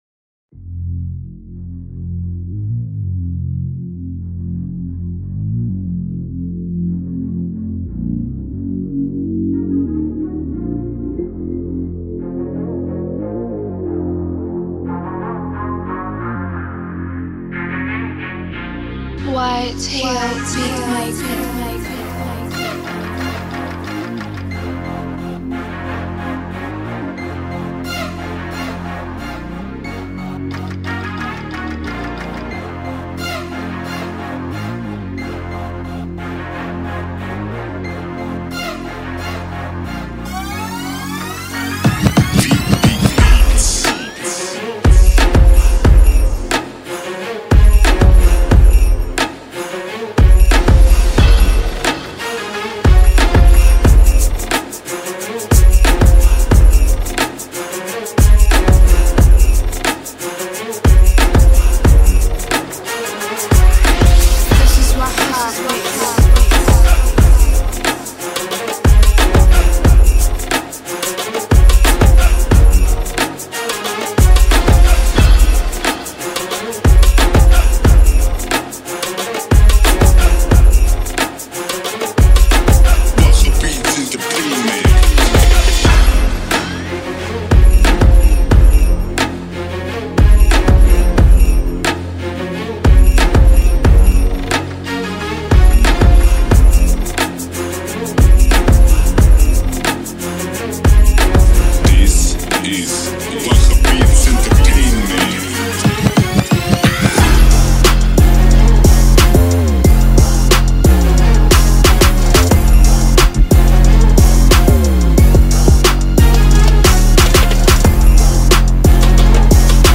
Carpeta: musica arabe mp3
Arabic Instrumental music Arab Trap Beat Mix